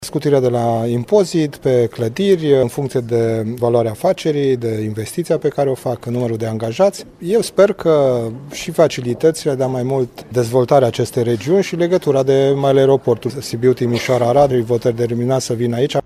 Autorităţile locale din Deva şi Hunedoara le oferă facilităţi şi încearcă, astfel să reducă şomajul. Dacă investiţiile sunt mai mari de 5 milioane de euro, taxele vor fi eliminate, spune primarul Devei, Petru Mărginean.